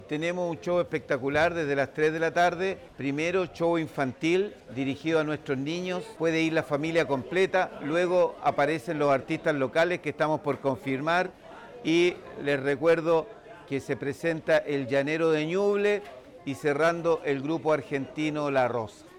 Asimismo, la autoridad comunal destacó el atractivo programa preparado para la jornada: